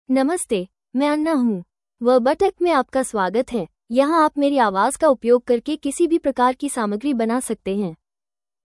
AnnaFemale Hindi AI voice
Anna is a female AI voice for Hindi (India).
Voice sample
Listen to Anna's female Hindi voice.
Female
Anna delivers clear pronunciation with authentic India Hindi intonation, making your content sound professionally produced.